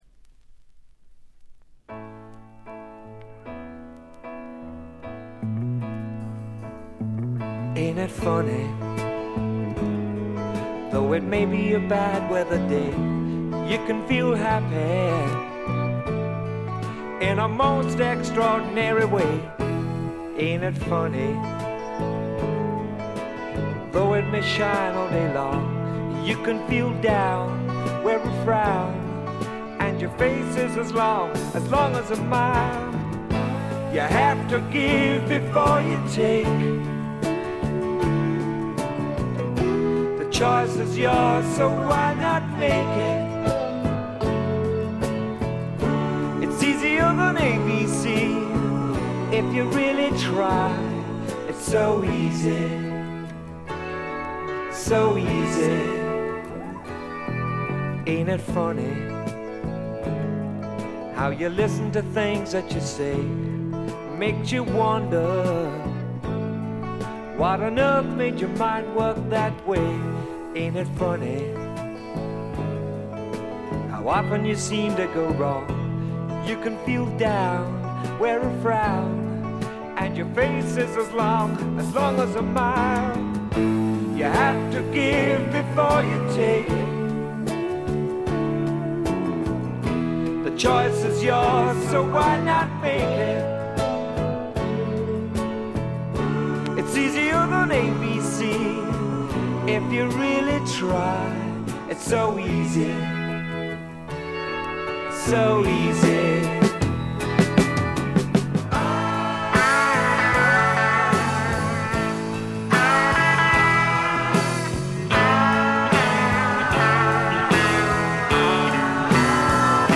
A1終了部で軽微なプツ音２回。
ほとんどノイズ感なし。
ピアノ系AOR系シンガー・ソングライターがお好きな方に大推薦です！
試聴曲は現品からの取り込み音源です。